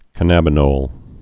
(kə-năbə-nôl, -nōl, -nŏl)